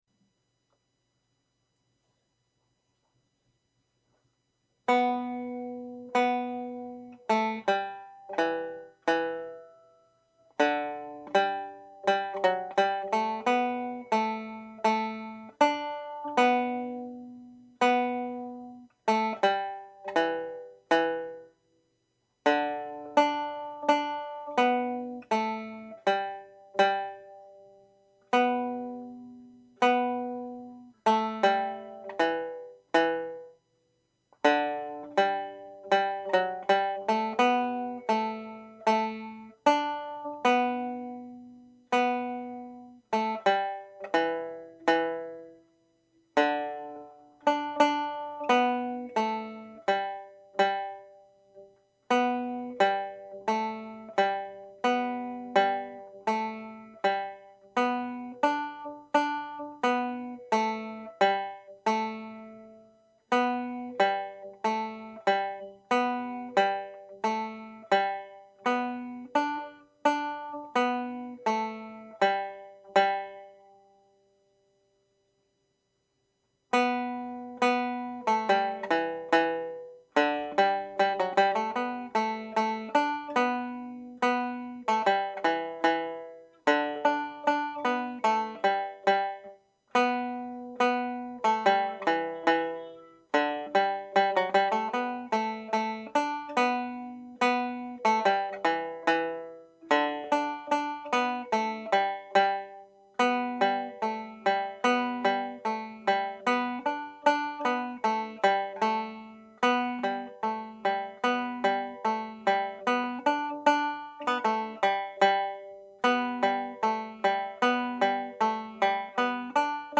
Pure Banjo » Polkas
Polkas
Siege of Ennis (G Major)